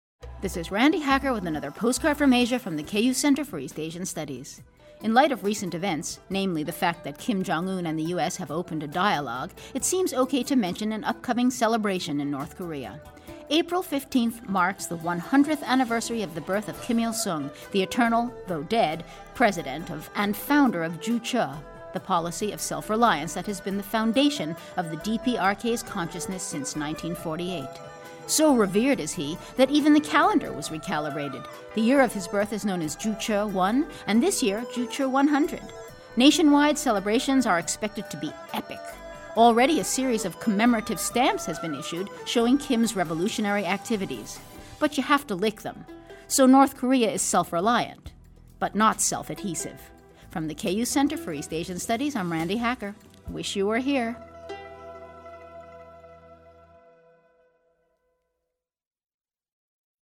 This is one of hundreds of 60-second radio spots created by the Center for East Asian Studies (CEAS) for Kansas Public Radio (KPR). The purpose of this outreach program is to introduce the people of Kansas to the culture and current issues of East Asia.